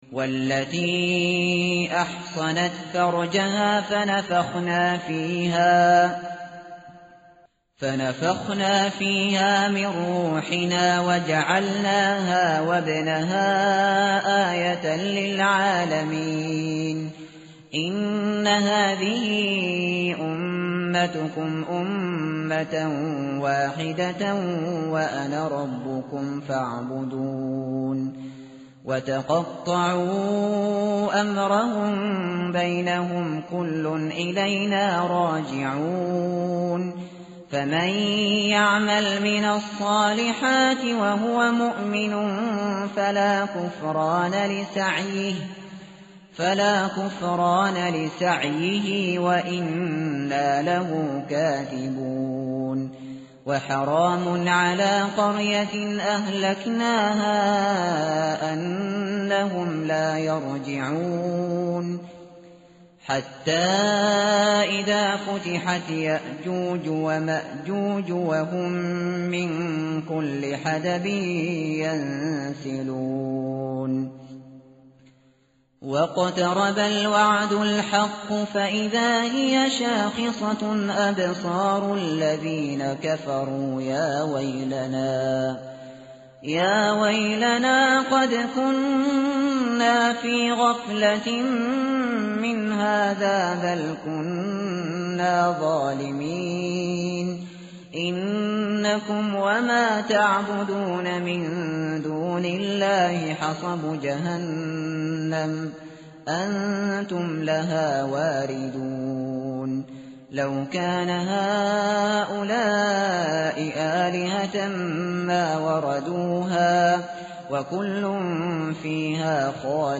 tartil_shateri_page_330.mp3